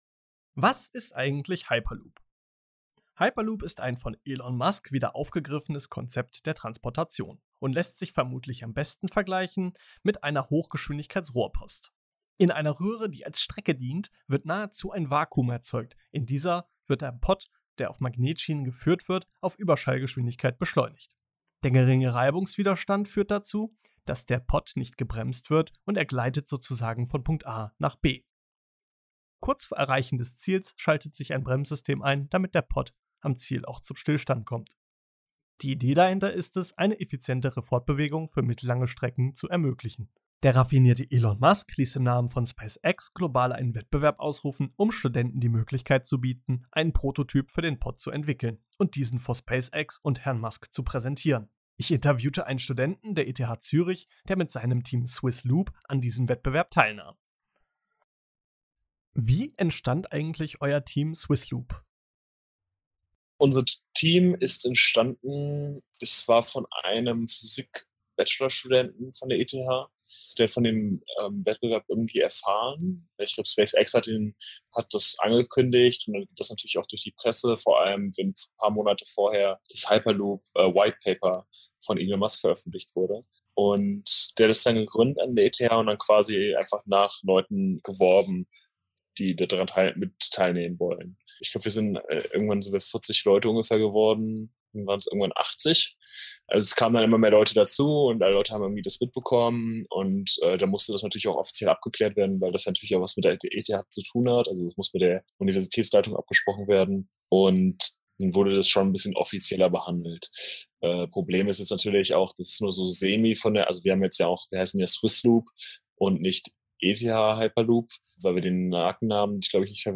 hat für Campus38 mit einem Wettbewerbsteilnehmer gesprochen.